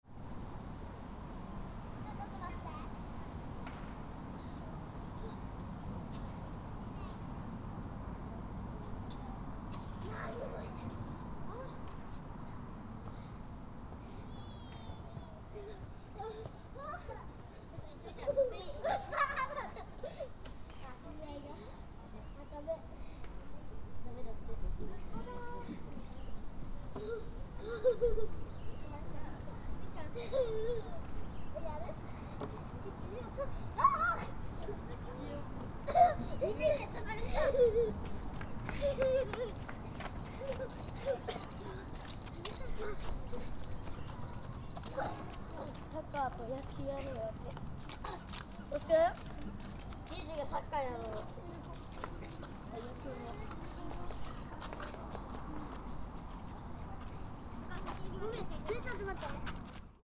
Many families with their children were playing cheerfully in the park. ♦ Unusually, only a few birds were singing around the park.